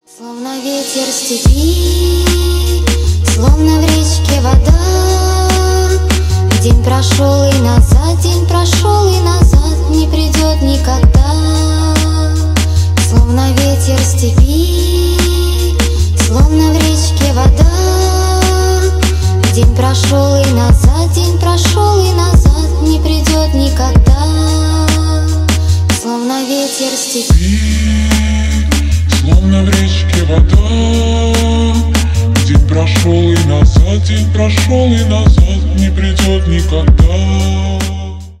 Ремикс
громкие # грустные